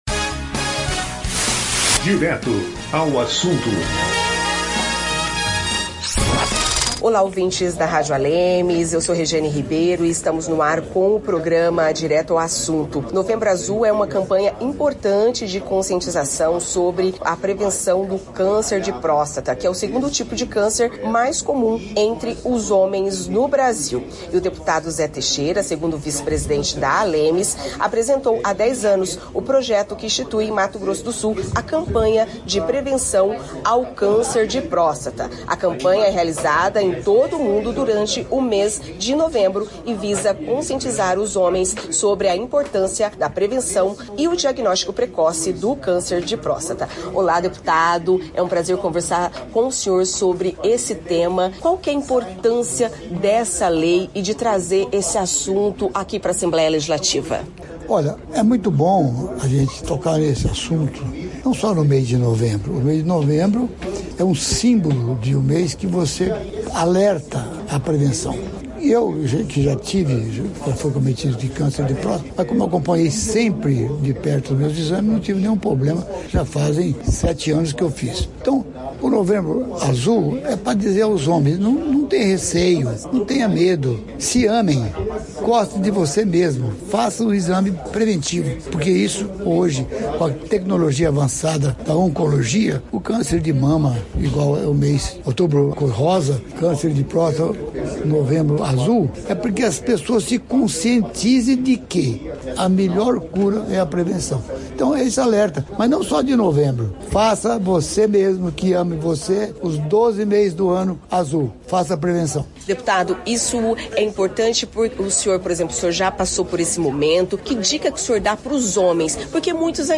Autor da lei que instituiu a campanha de prevenção ao câncer de próstata no Estado, o deputado Zé Teixeira relembra que o Novembro Azul é fundamental para incentivar os homens a cuidarem da saúde e realizarem o diagnóstico precoce da doença, que é o segundo tipo de câncer mais comum entre eles no Brasil.